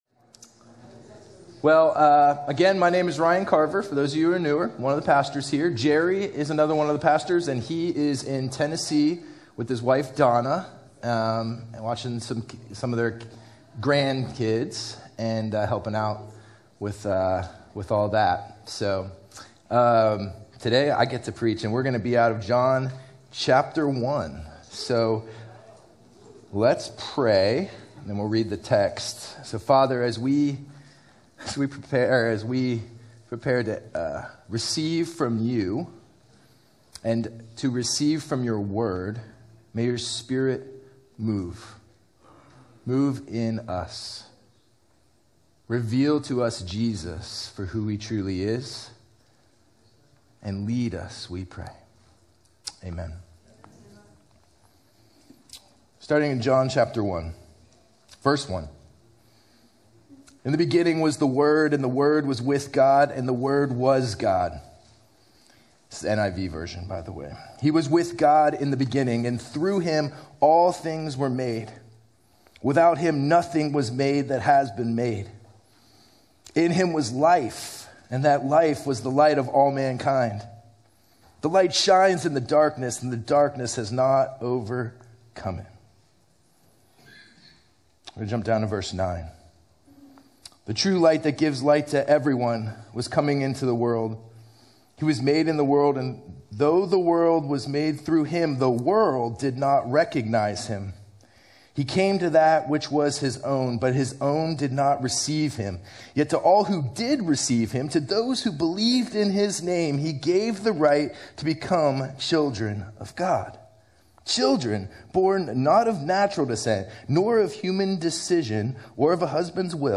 Sermon: When Meaning Takes Flesh (John 1:1-14, 20-21)